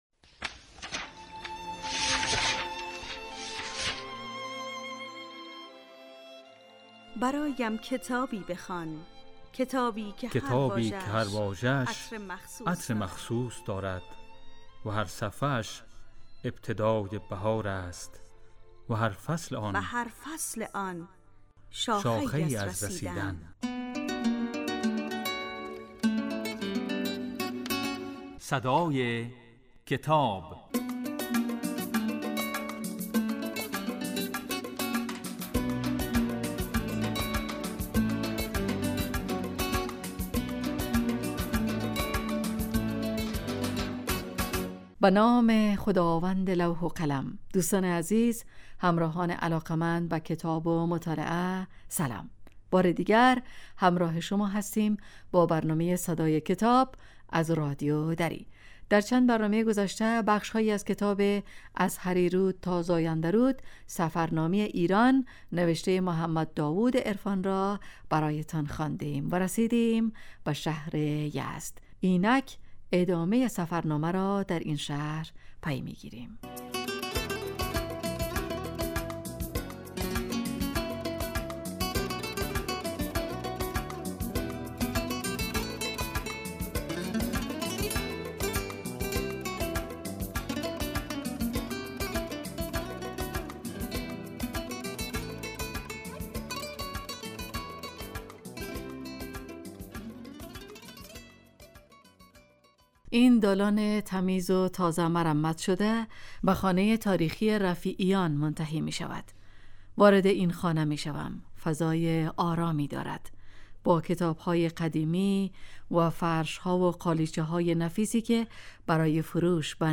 این برنامه کتاب صوتی است و در روزهای سه شنبه و پنج شنبه در بخش صبحگاهی پخش و در بخش نیمروزی بازپخش می شود.